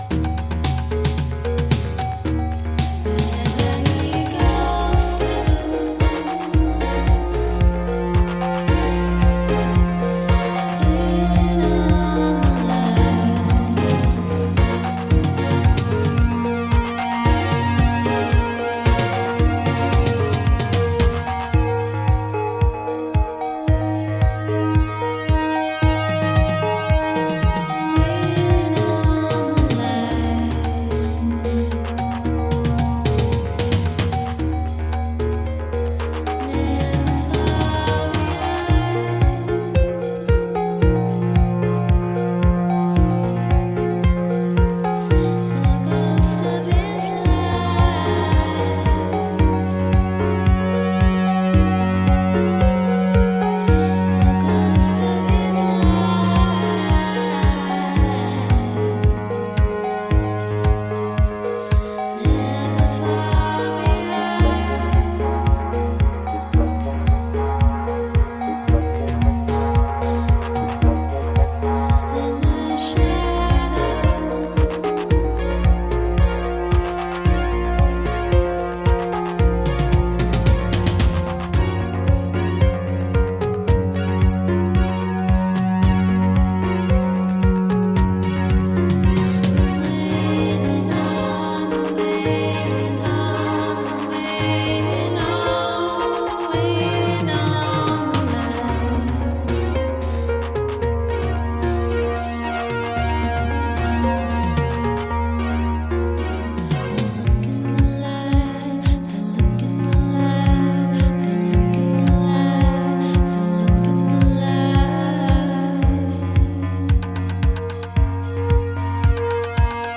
Disco Electro Indie